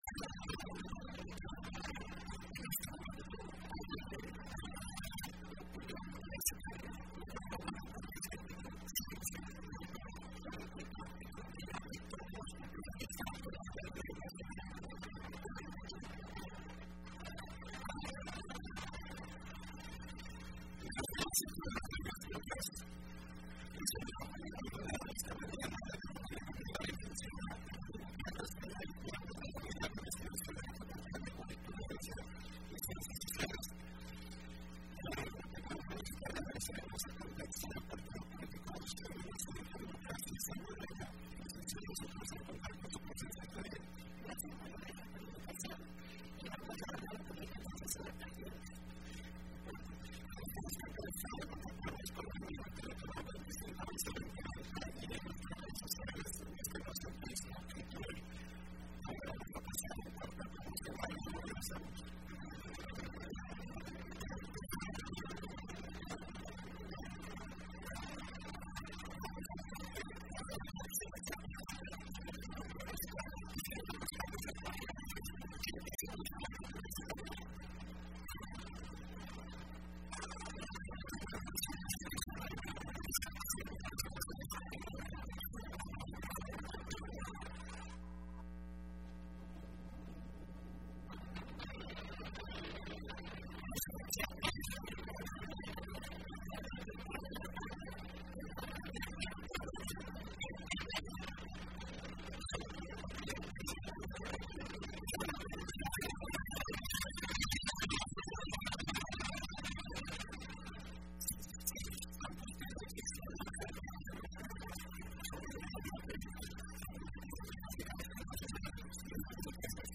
Entrevista Opinión Universitaria (4 febrero 2015): Contexto de los Partidos Políticos en la Democracia Salvadoreña